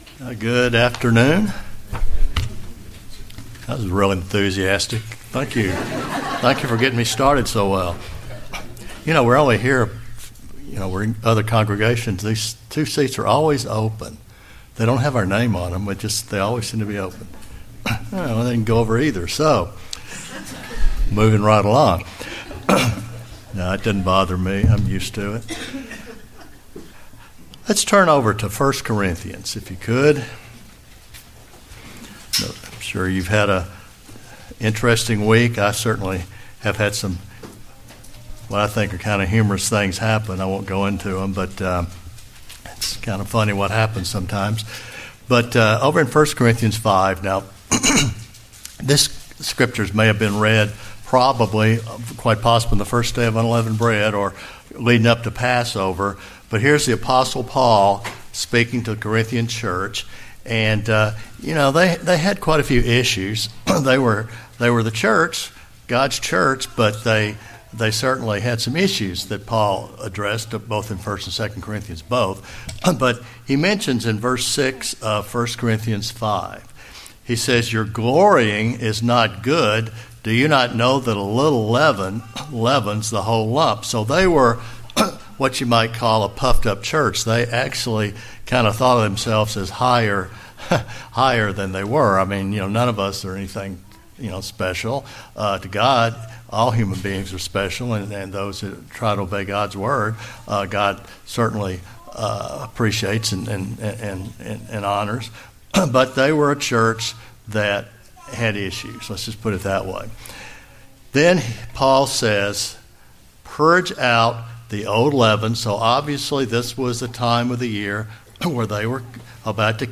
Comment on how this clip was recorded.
Given in Huntsville, AL